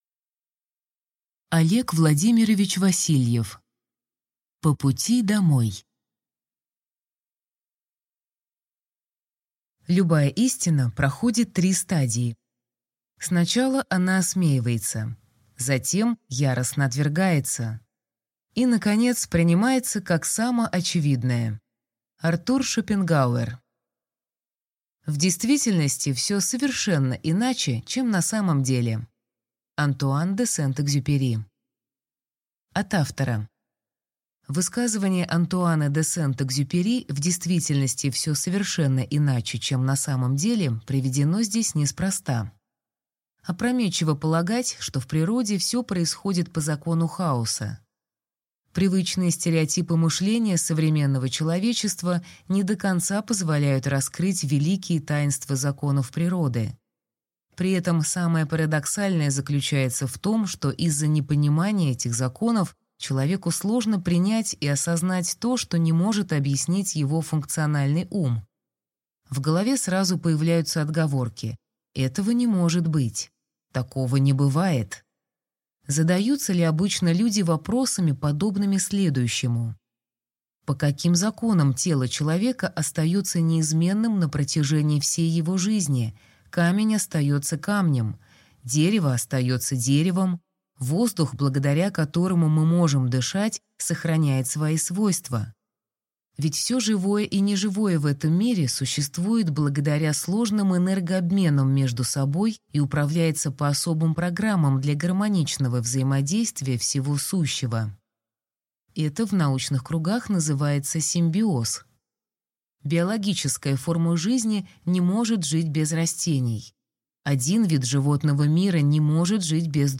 Аудиокнига По пути домой | Библиотека аудиокниг